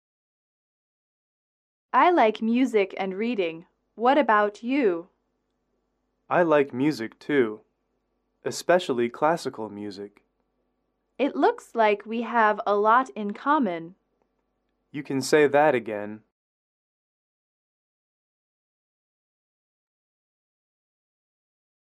英语口语情景短对话04-3：相同爱好